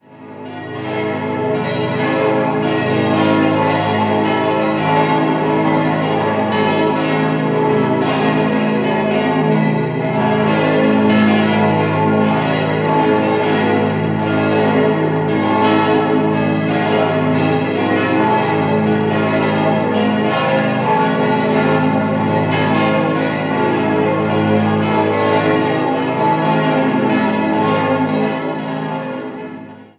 6-stimmiges Geläut: a°-cis'-e'-fis'-gis'-h' Die Glocken wurden 1933 von der Gießerei Rüetschi in Aarau gegossen.